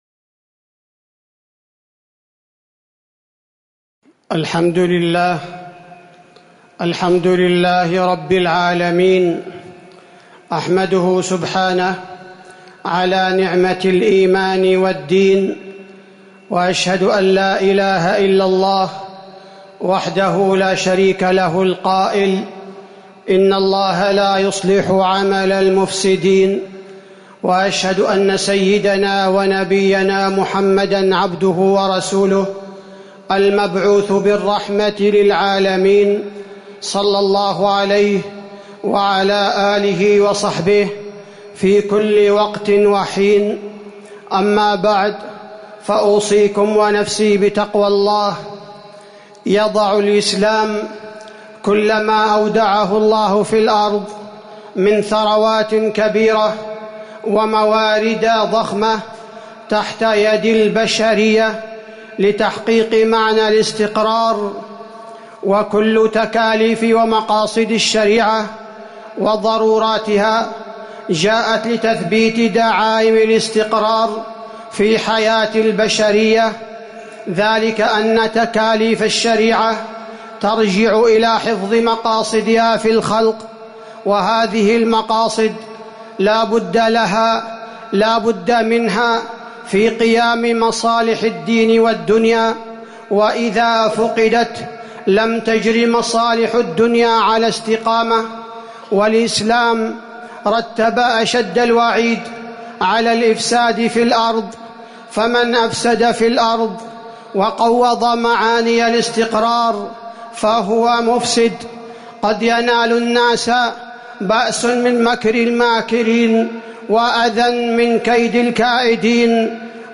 تاريخ النشر ٢١ محرم ١٤٤١ هـ المكان: المسجد النبوي الشيخ: فضيلة الشيخ عبدالباري الثبيتي فضيلة الشيخ عبدالباري الثبيتي أحداث بقيق وخريص